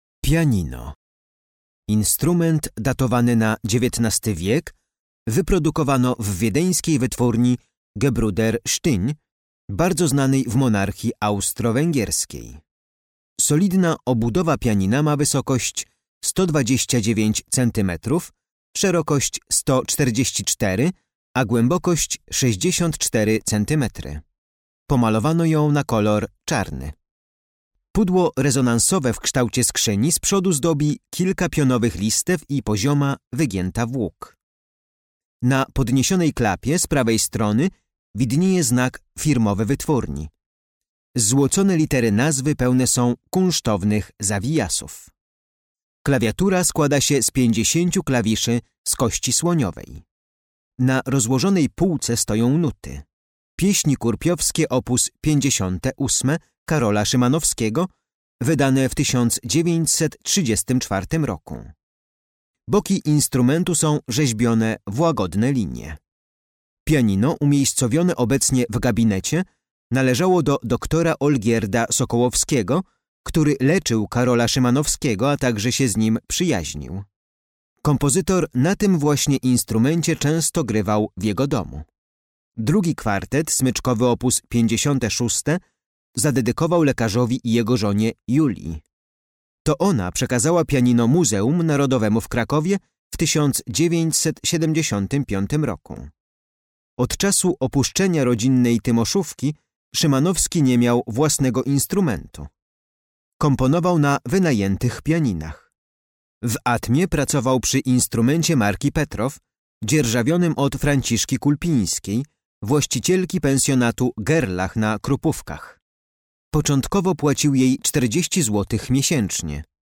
Audiodeskrypcja dla wybranych eksponatów z kolekcji MNK znajdujących się w Muzeum Karola Szymanowskiego w willi "Atma" w Zakopanem.